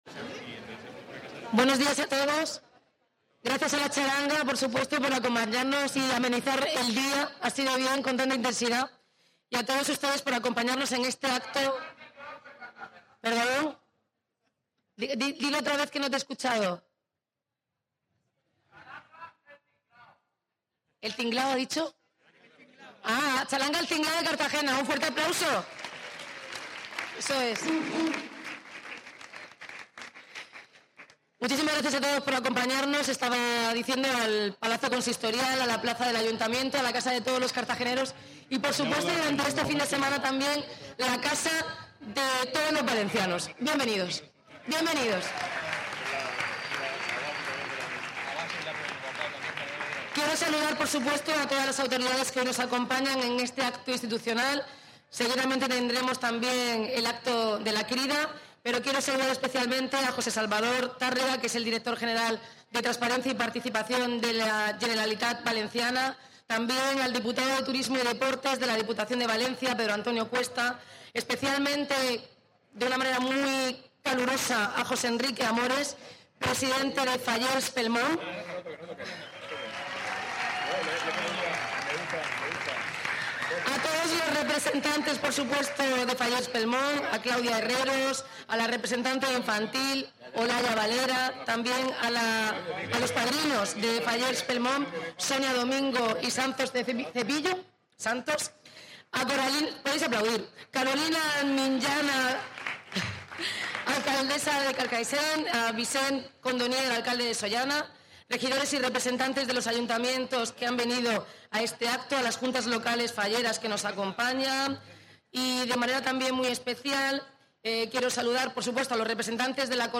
Audio: Declaraciones